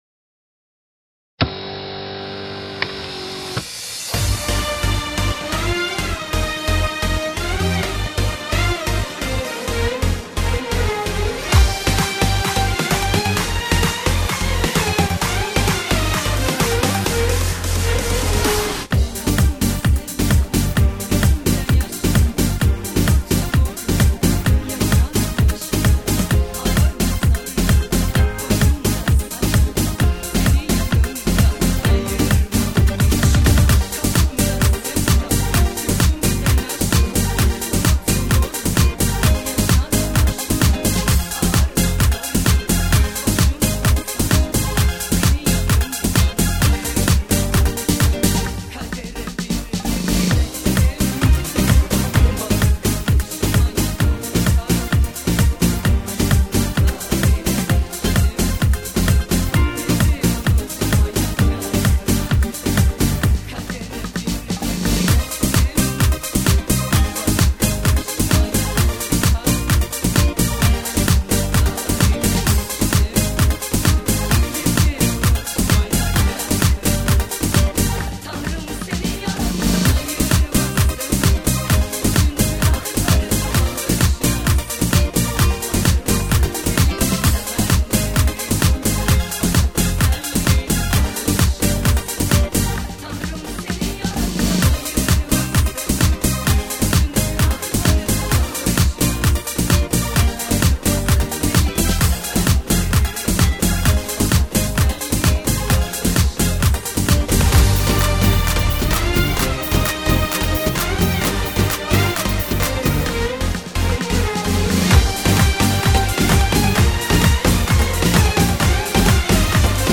biraz vokal bıraktım takip etmeniz için.